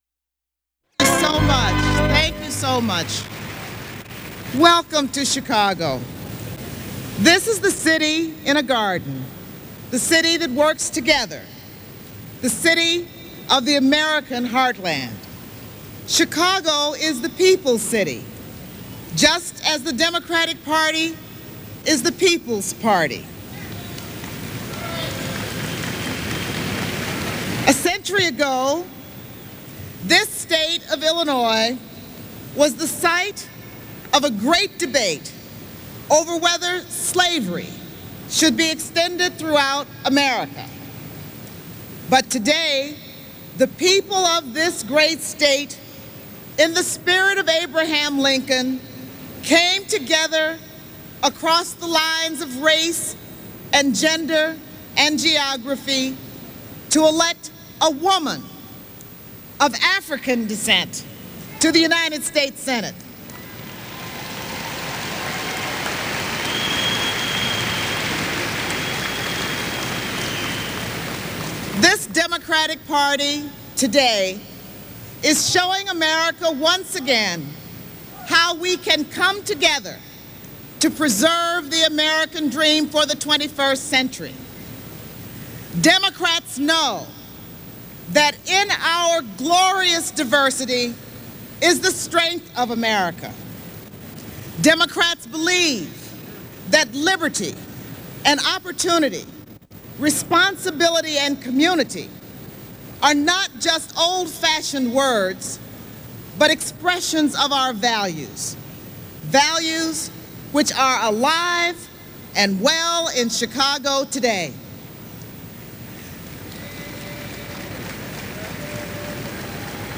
Read In Collections G. Robert Vincent Voice Library Collection Copyright Status No Copyright Date Published 1996-08-26 Speakers Moseley-Braun, Carol, 1947- Contributors Democratic National Convention (1996 : Chicago, Ill.)
Democratic National Convention Politics and government United States Material Type Sound recordings Language English Extent 00:03:00 Venue Note Broadcast on C-Span, August 26, 1996.